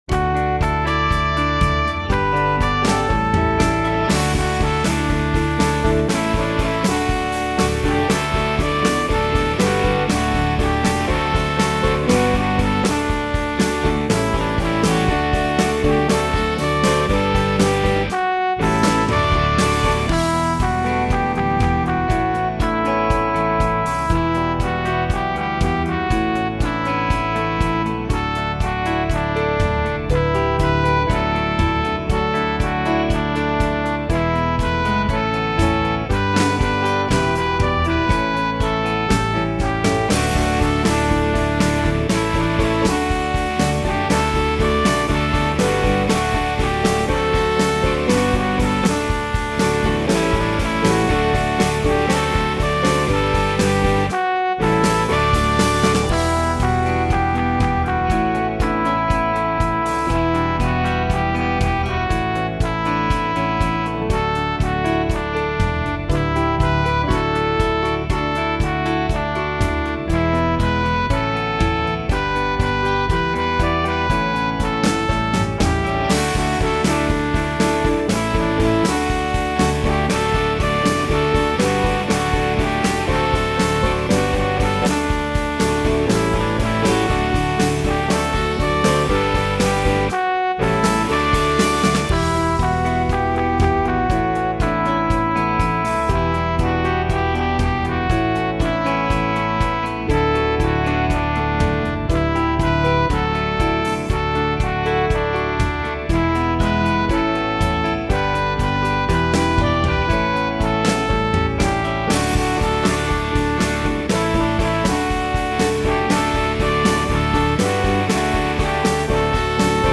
This is a children’s song by Christopher Walker that turns up unexpectedly and gives the impression that the end of this collection gets a bit random.
The first sentence in the verse is sung solo and then repeated by the assembly.